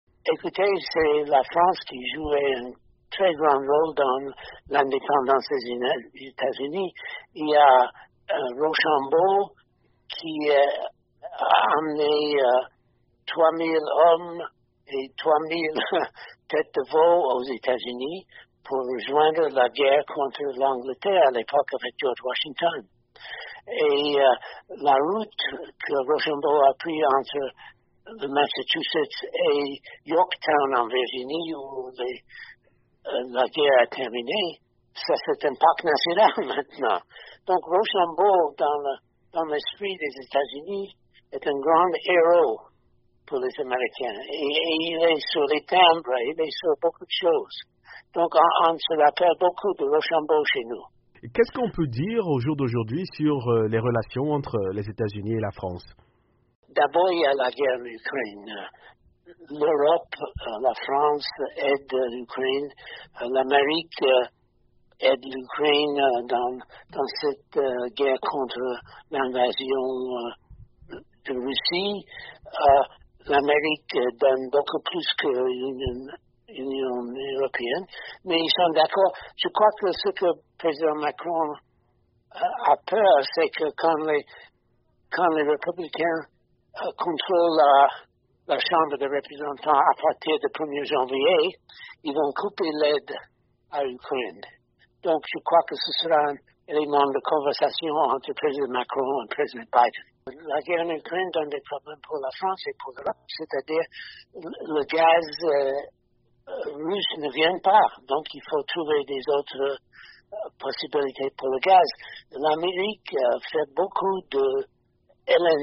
a joint au telephone Herman Cohen, ancien ambassadeur américain.